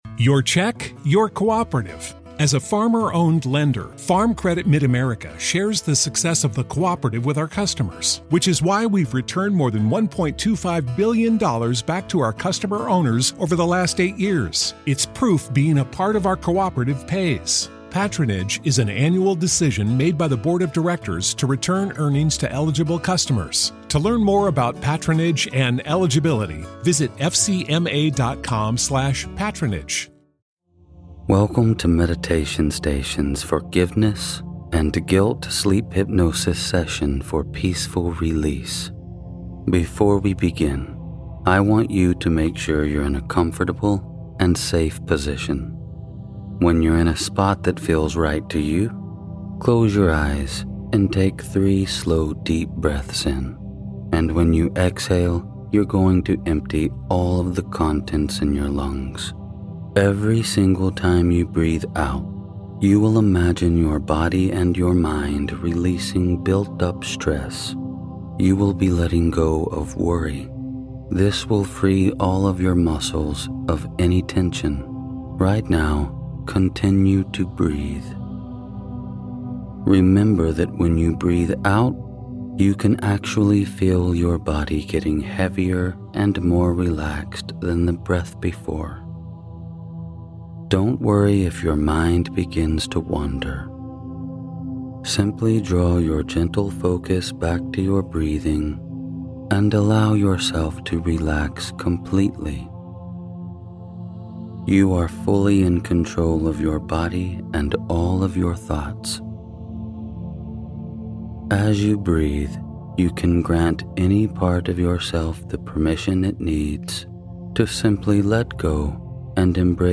This sleep hypnosis is your key to forgiveness.